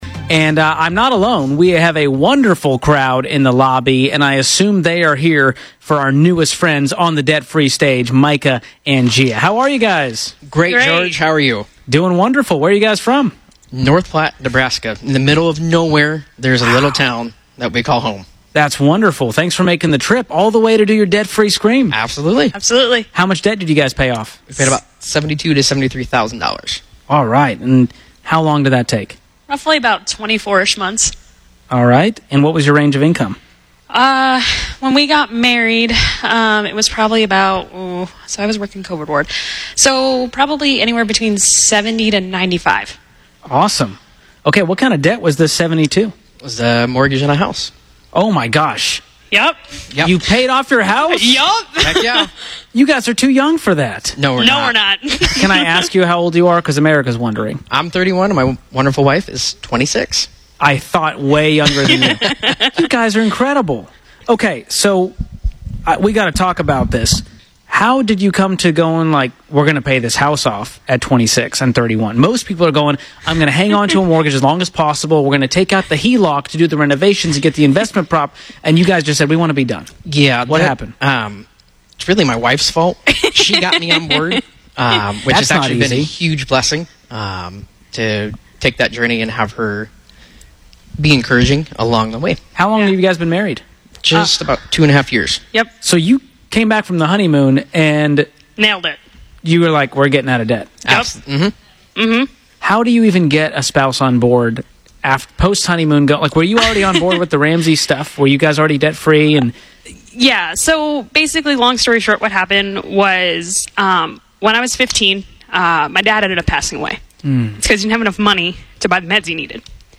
The show, which focuses on finances, regularly features listeners who have followed the Ramsey plan and ultimately become debt free. Ramsey personalities bring those in for a “debt free scream” to tell their story of how they got out of debt.